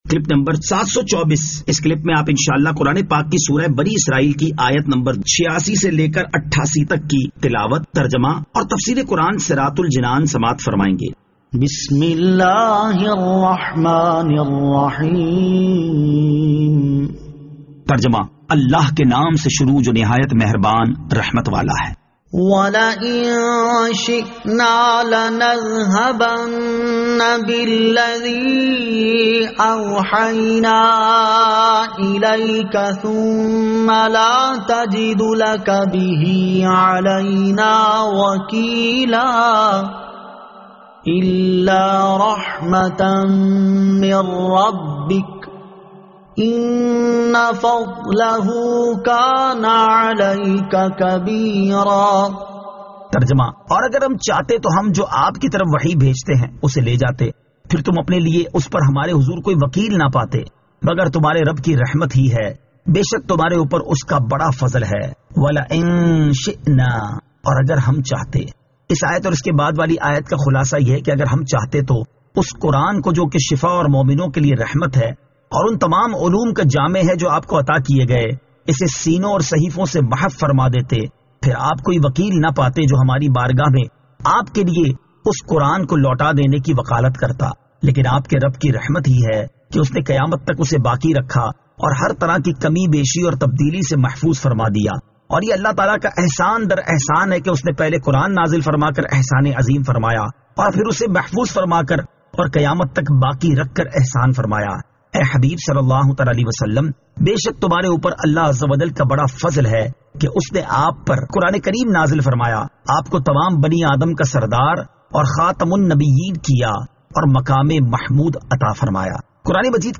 Surah Al-Isra Ayat 86 To 88 Tilawat , Tarjama , Tafseer
2021 MP3 MP4 MP4 Share سُوَّرۃُ الاسٗرَاء آیت 86 تا 88 تلاوت ، ترجمہ ، تفسیر ۔